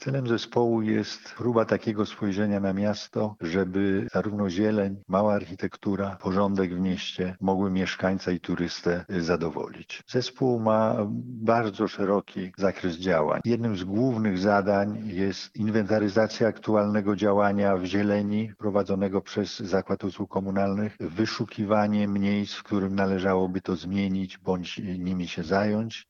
O zadaniach zespołu mówi Zastępca Prezydenta Miasta Stargard Piotr Mync: